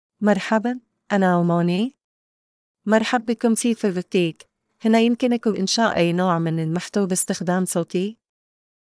Amany — Female Arabic (Syria) AI Voice | TTS, Voice Cloning & Video | Verbatik AI
FemaleArabic (Syria)
Amany is a female AI voice for Arabic (Syria).
Voice sample
Amany delivers clear pronunciation with authentic Syria Arabic intonation, making your content sound professionally produced.